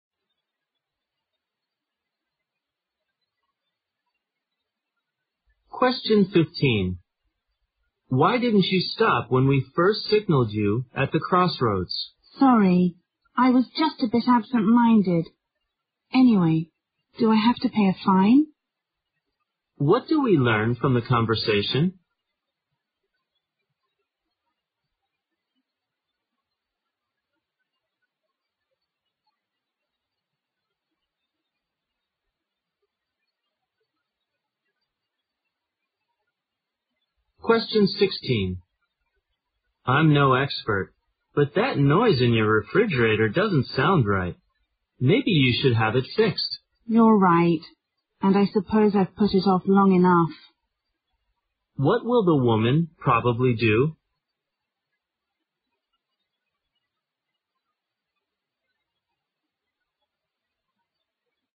在线英语听力室030的听力文件下载,英语四级听力-短对话-在线英语听力室